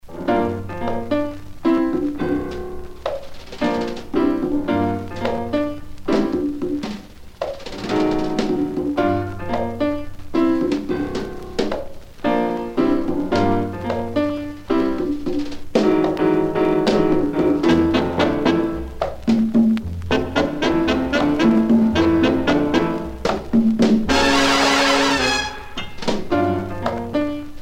danse
Pièce musicale éditée